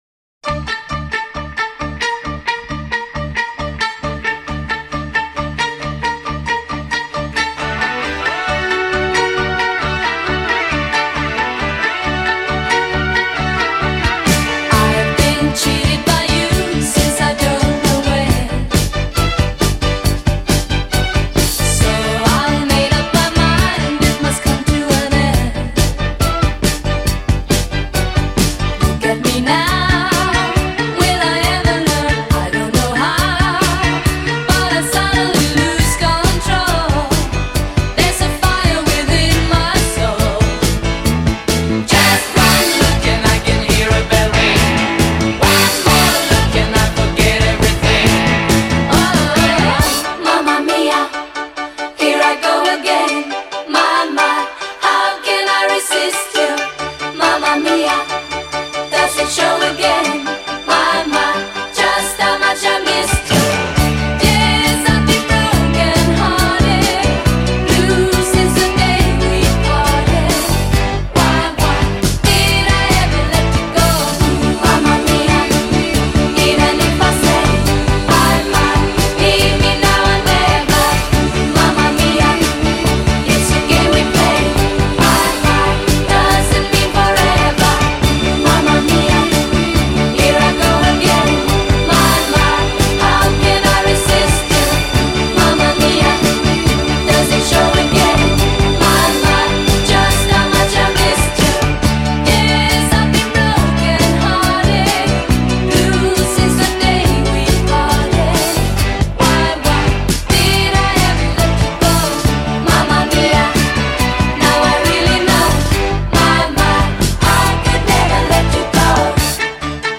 BPM126-146
Audio QualityPerfect (High Quality)
137ish BPM